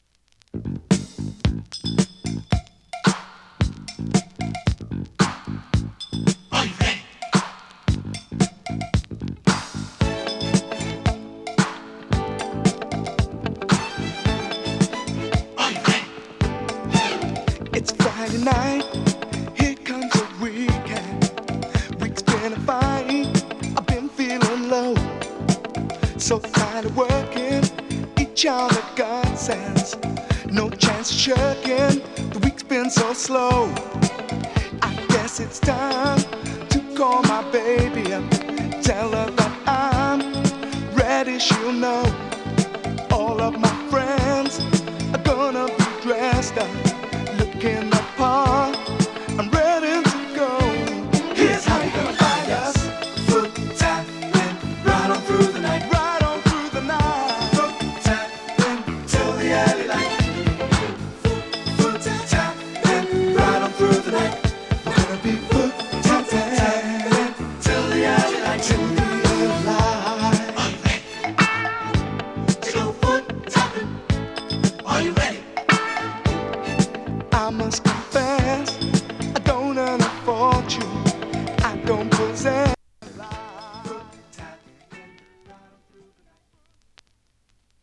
[2track 7inch]＊音の薄い部分で軽いチリパチ・ノイズ。